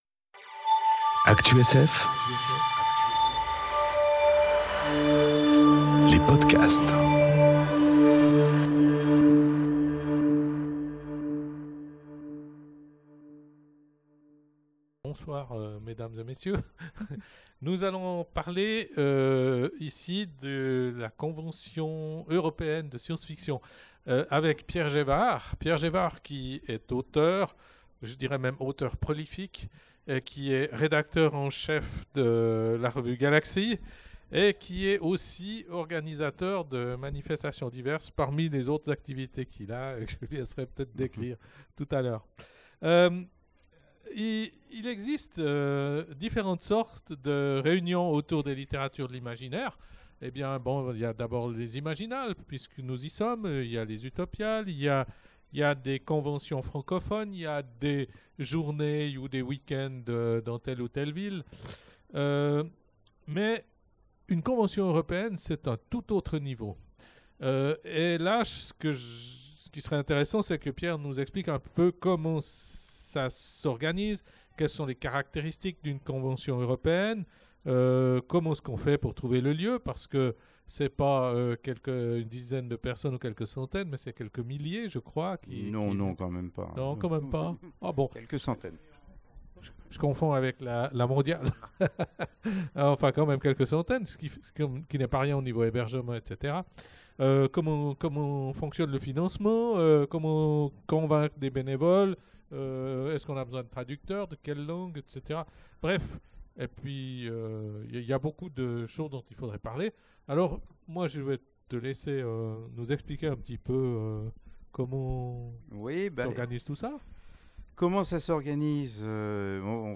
Conférence Eurocon, Nemo 2018 enregistrée aux Imaginales 2018